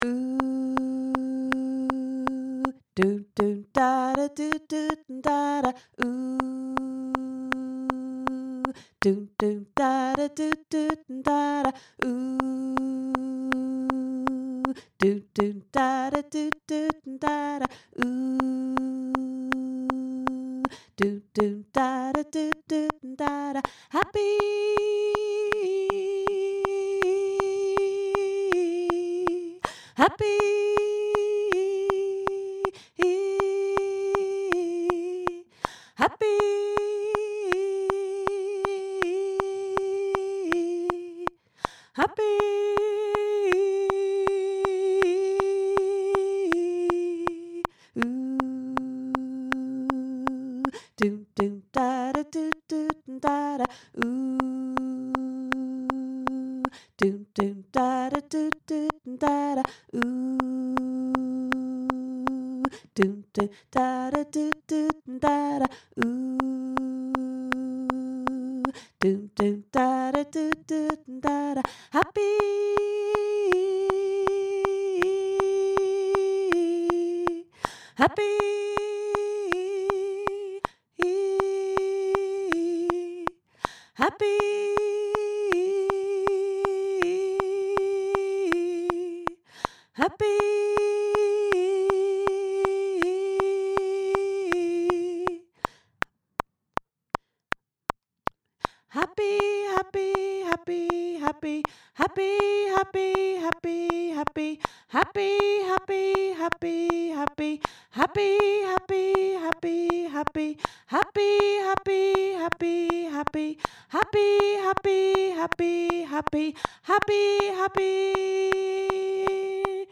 happy-breakaway-alto